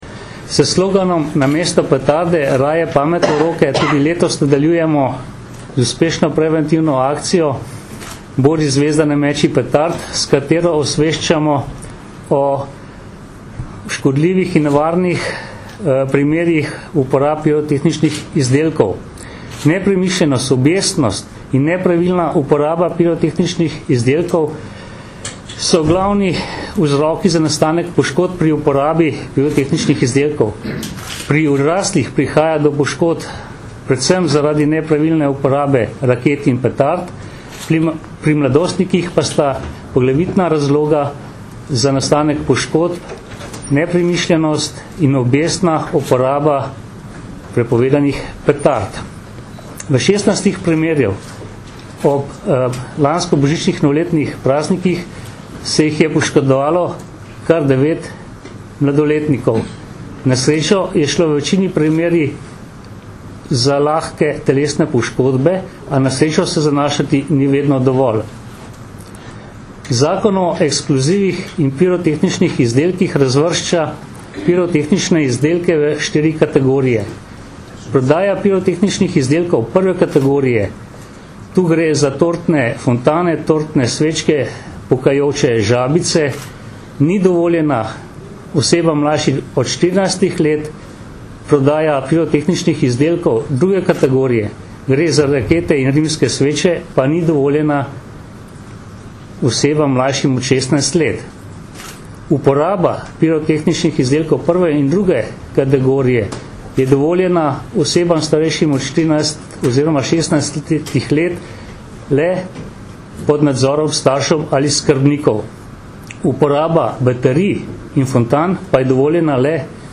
V decembrskem času začenjamo že tradicionalno akcijo Bodi zvezda, ne meči petard!, namenjeno osveščanju o nevarnostih uporabe pirotehničnih izdelkov, ki smo jo skupaj s strokovnjaki UKC Ljubljana predstavili na današnji novinarski konferenci.